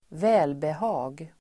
Uttal: [²v'ä:lbeha:g]